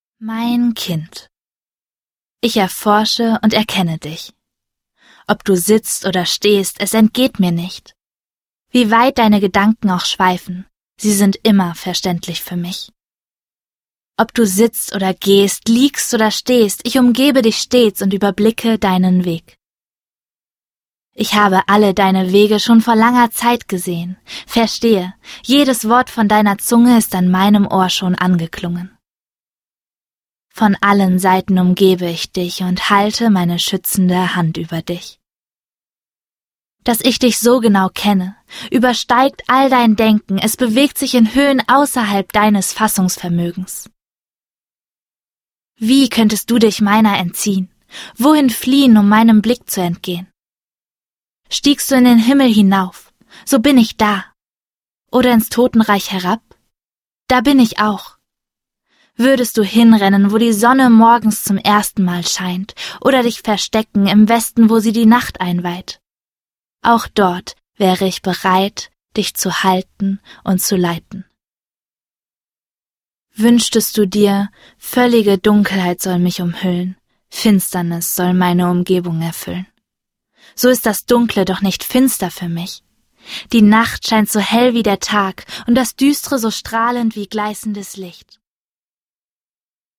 Perspektivwechsel (MP3-Hörbuch - Download)